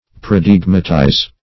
Search Result for " paradigmatize" : The Collaborative International Dictionary of English v.0.48: Paradigmatize \Par`a*dig"ma*tize\, v. t. [imp.
paradigmatize.mp3